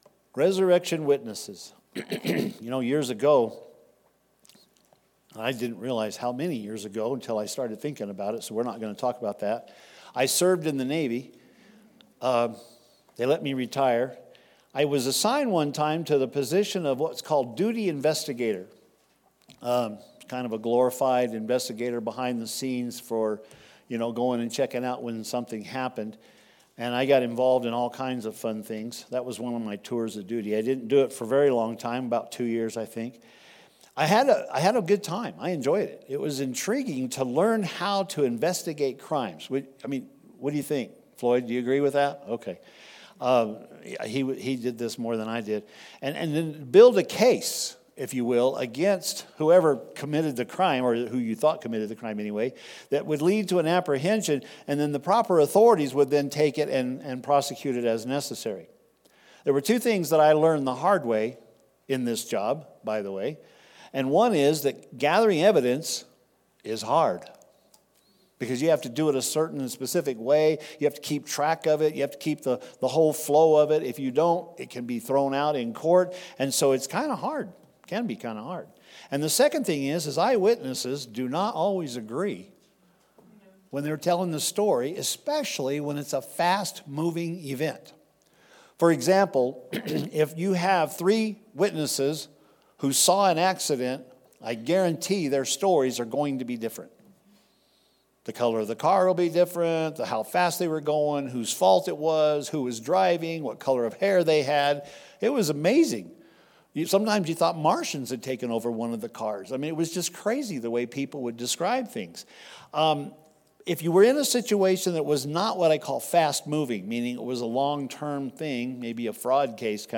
Celebrate the resurrection of Christ in this Easter Sunday message.